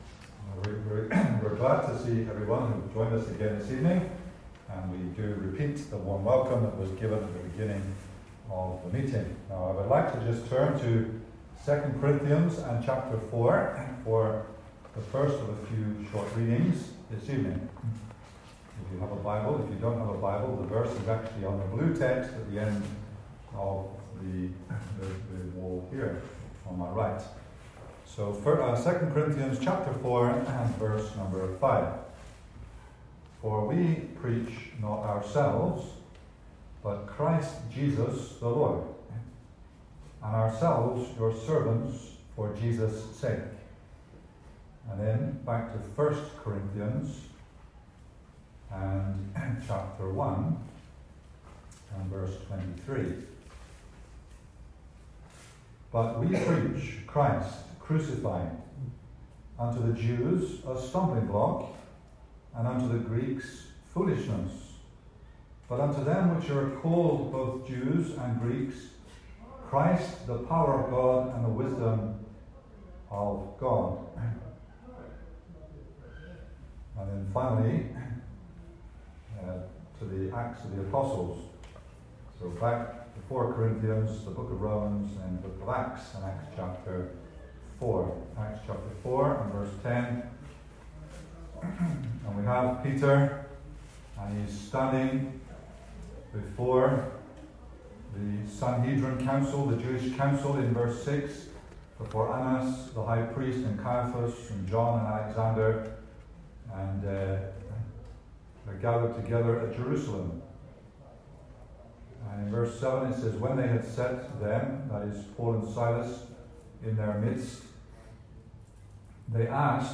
Service Type: Gospel
In the second part of the meeting, the speaker focuses his comments on the verse ‘We preach not ourselves, but Christ Jesus the Lord’.